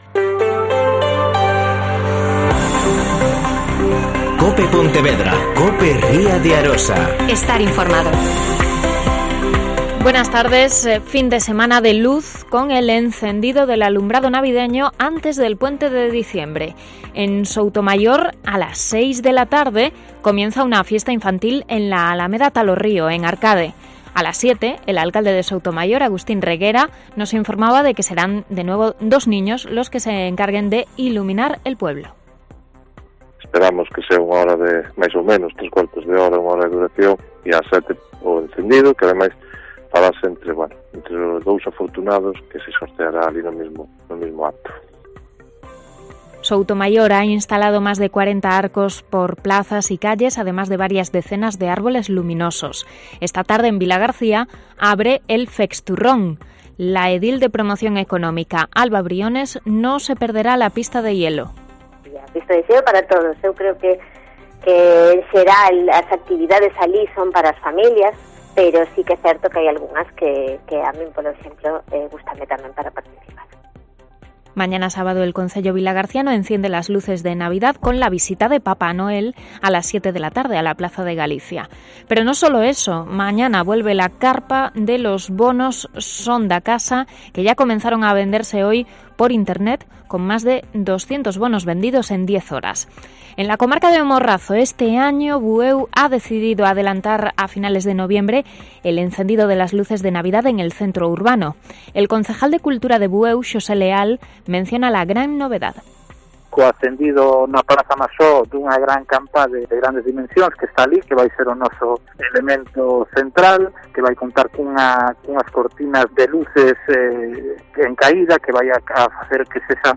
Mediodía COPE Pontevedra y COPE Ría de Arosa (Informativo 14:20h.)